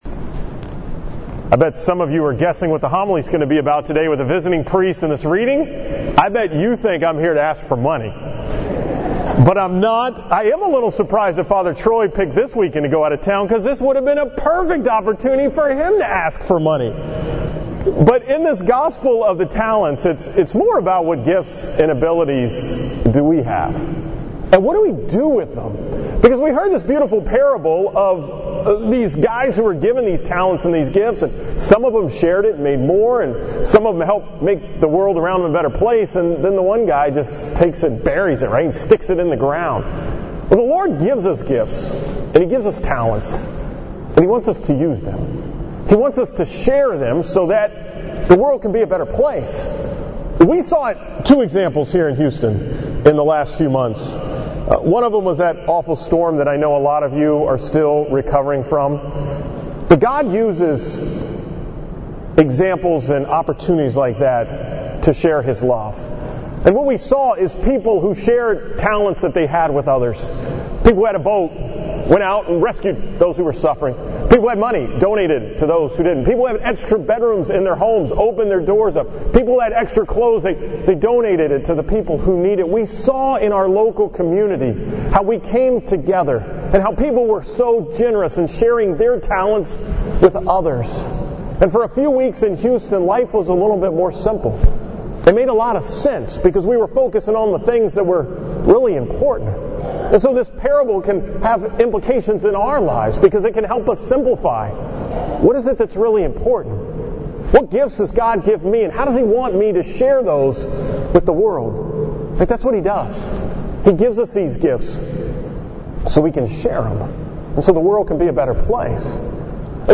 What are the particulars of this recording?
From Mass on the 33rd Sunday in Ordinary Time at St. John Vianney